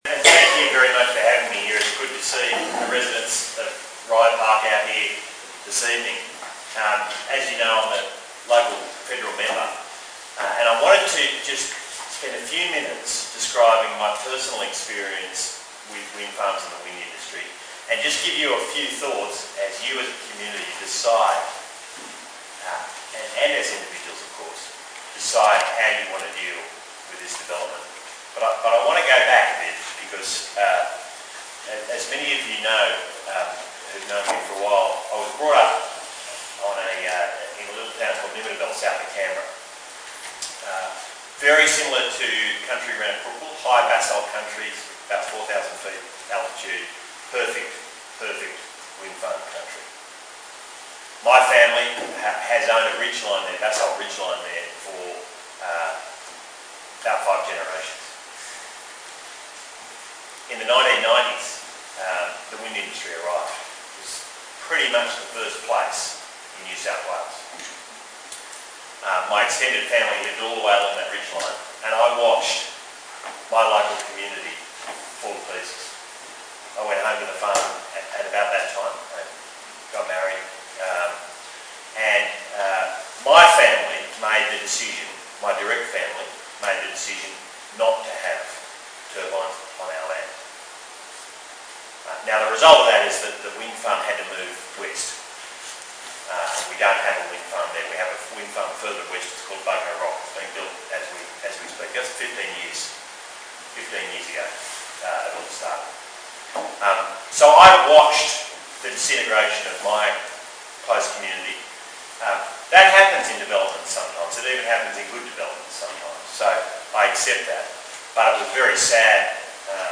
With respect to the operative behind the video camera, the audio and video of his talk are pretty average in quality, so we’ve transcribed what Angus had to say and that transcript follows:
Rye Park Community Meeting: 6 June 2014: Angus Taylor